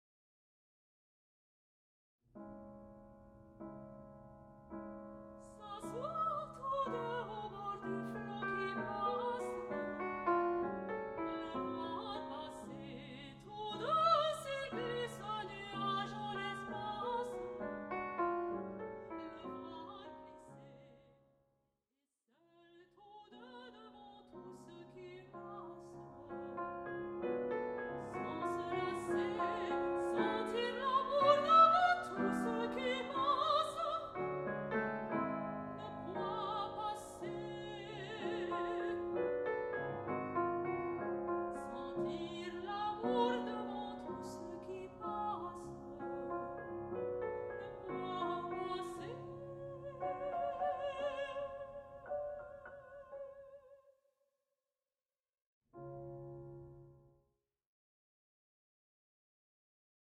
La mélodie française prend un parfum surnaturel, quelques gouttes d'humour noir versées dans une coupe, un philtre délicieux à partager avec le public.
piano
chant